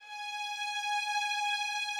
Added more instrument wavs
strings_068.wav